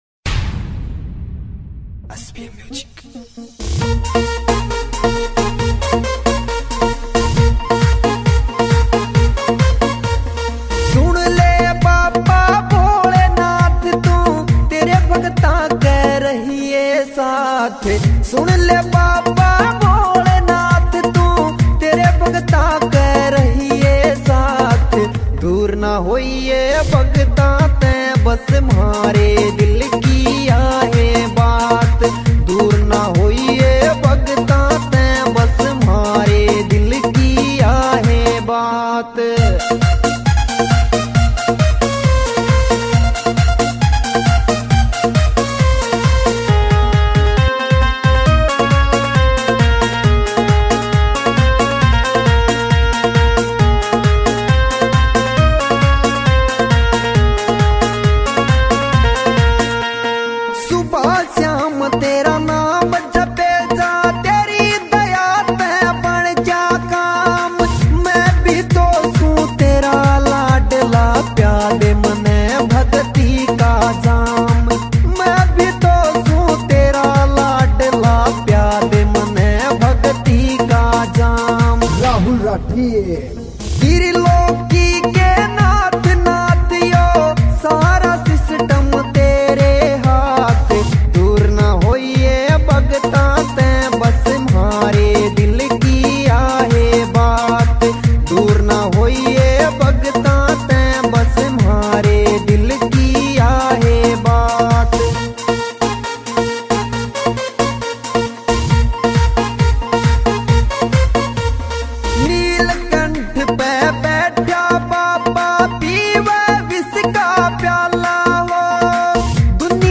[ Bhakti Songs ]